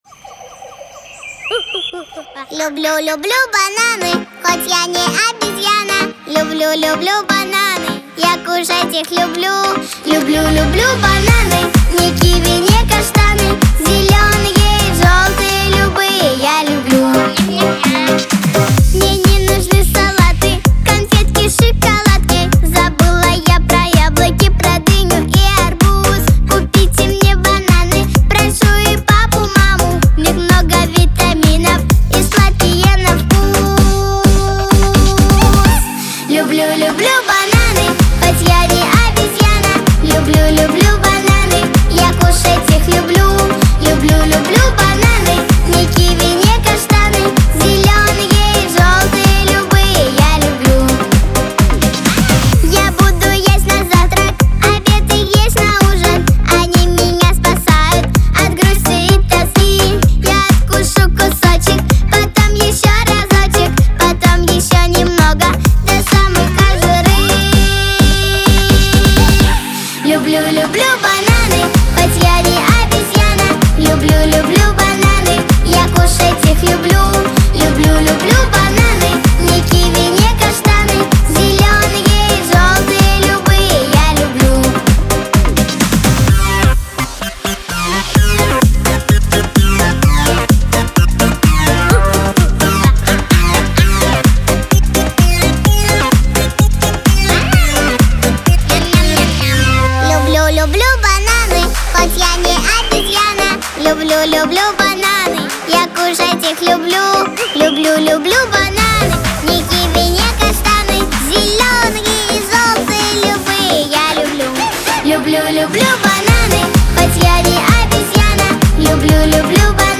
диско